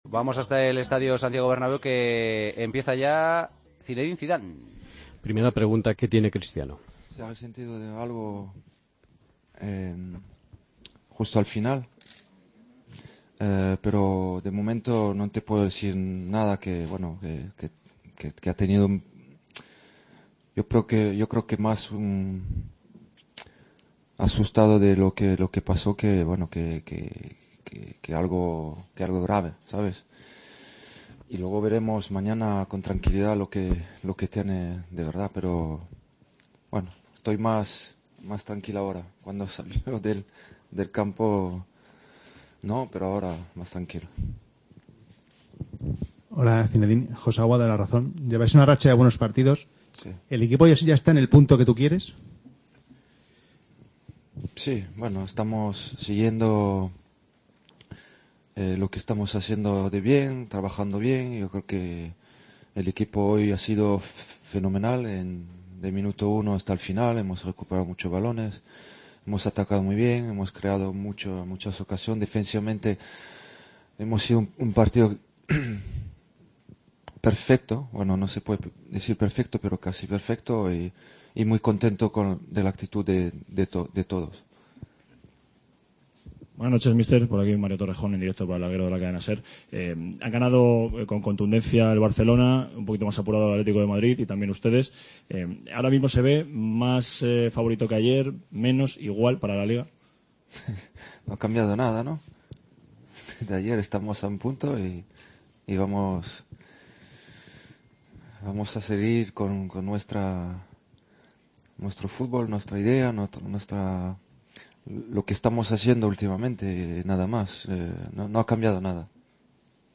Zidane explicó en rueda de prensa cómo se encuentra Cristiano Ronaldo, que abandonó el césped lesionado: "Cristiano ha sentido algo al final, asustado cuando salió del campo, pero ahora estoy más tranquilo, creo que sólo ha sido un susto.